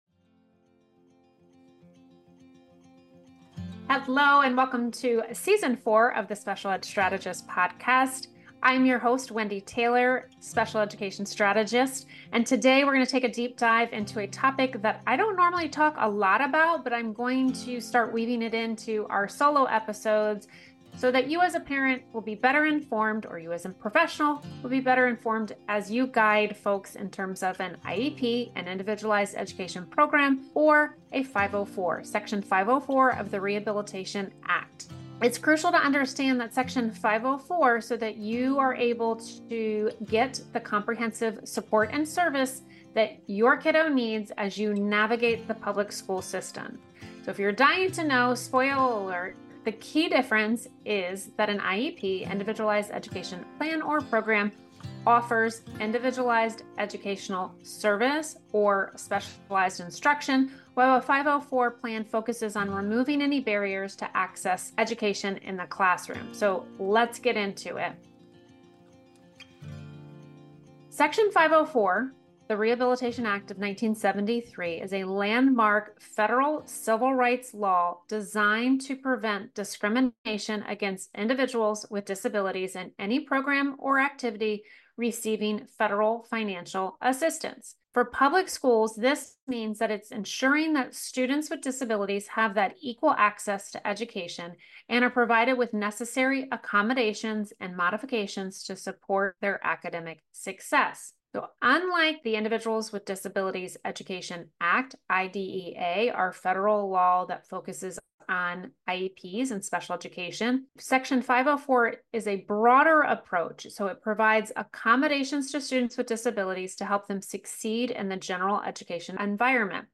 This solo episode breaks down everything you need to know about how 504 Plans differ from IEPs, how they work, and how you can effectively advocate for your child's rights in the public school system.